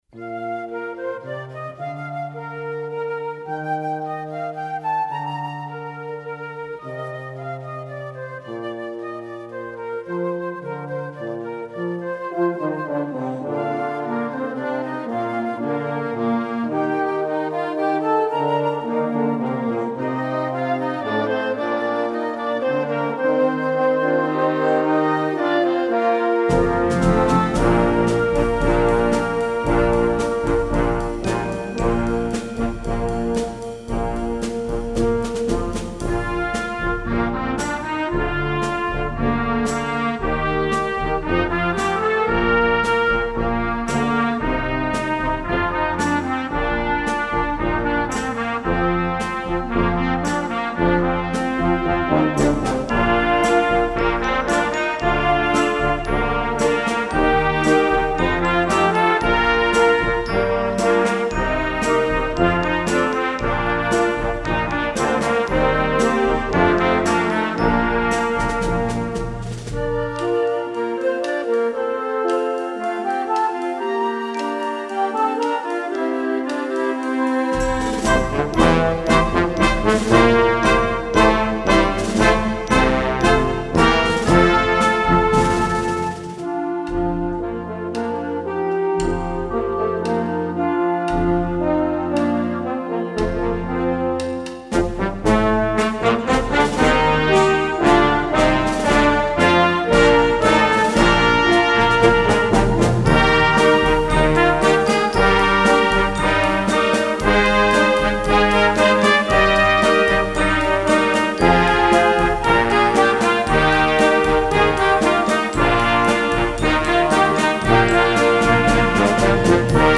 Besetzung: Blasorchester
great little rock tune